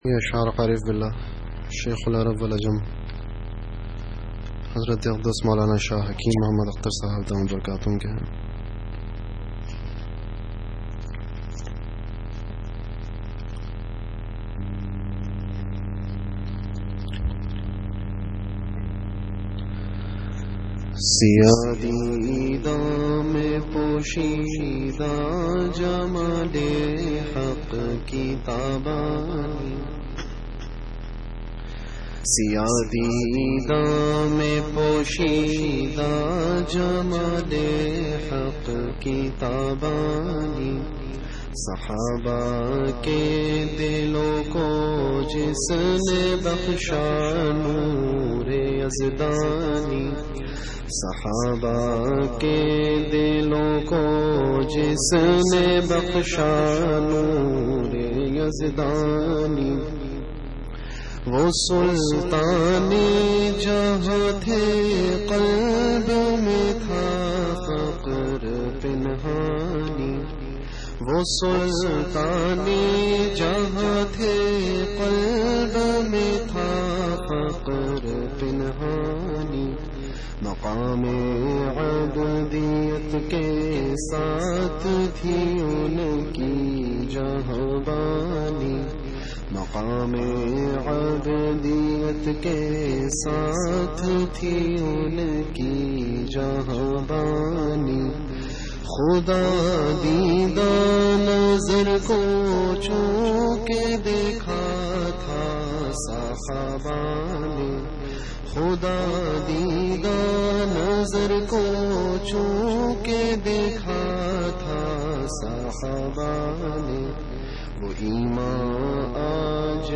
Delivered at Jamia Masjid Bait-ul-Mukkaram, Karachi.